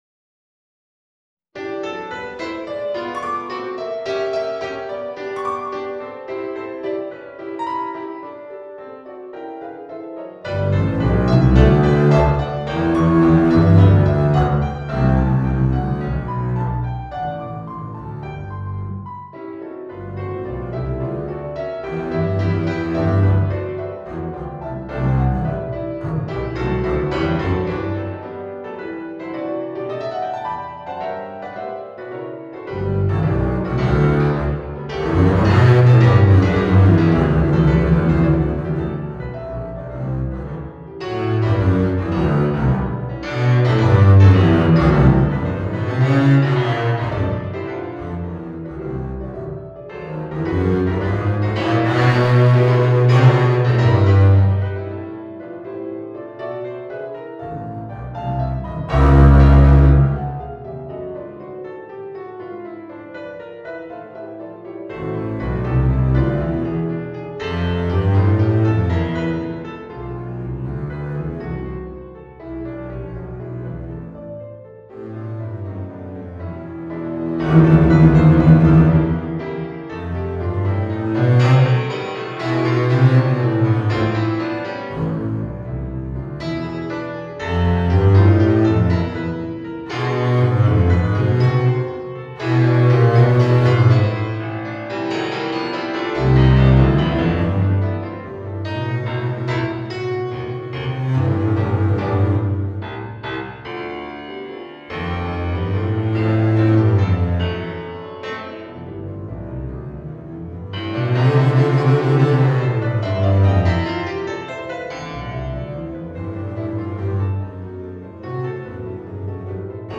ストリングベース+ピアノ